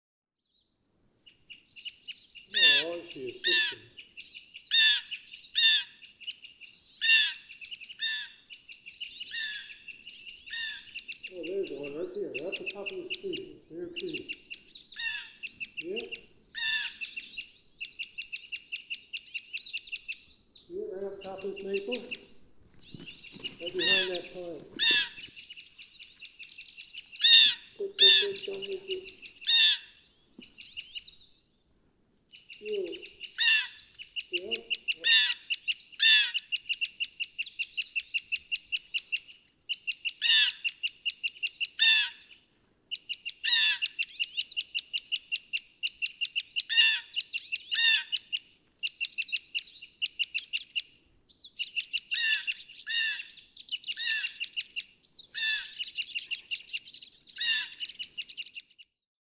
Red crossbill
With calling siskins and jays, and enthusiastic birders.
Marsh-Billings-Rockefeller National Historic Park, Woodstock, Vermont.
334_Red_Crossbill.mp3